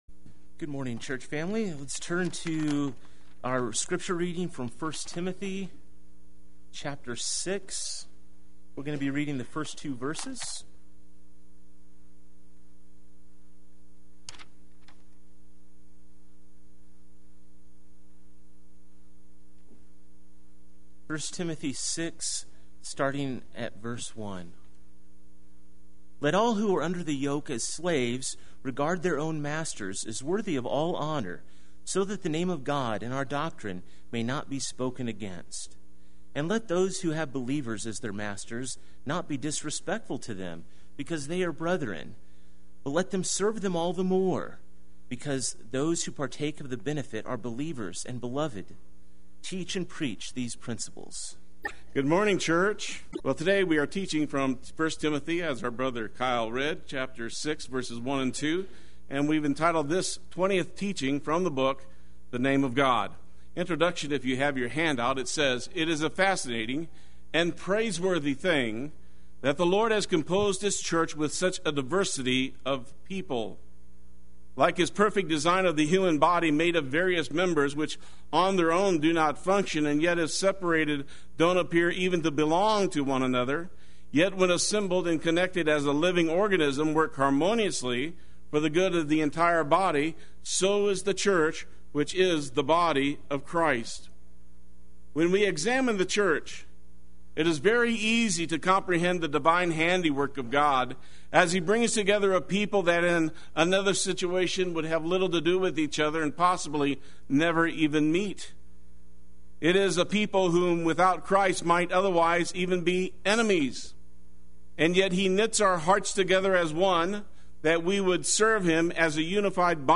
Play Sermon Get HCF Teaching Automatically.
The Name of God Sunday Worship